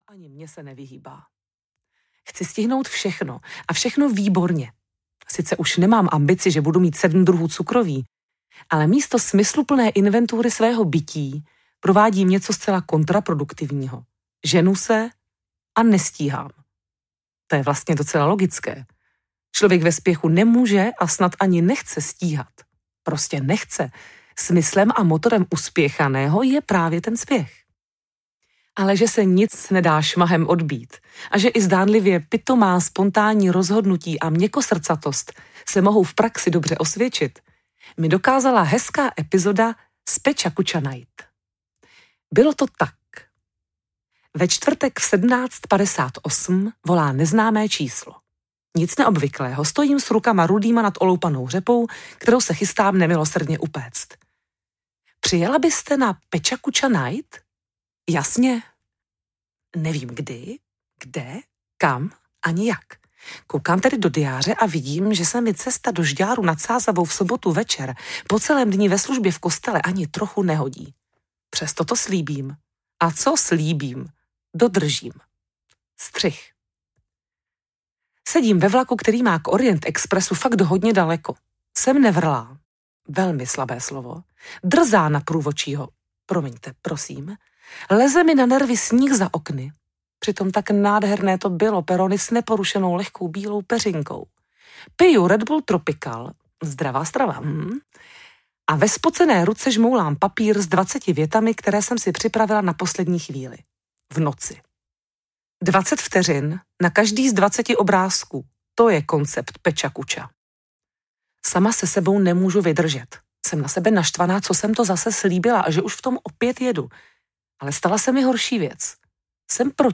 Deník farářky audiokniha
Ukázka z knihy
denik-fararky-audiokniha-0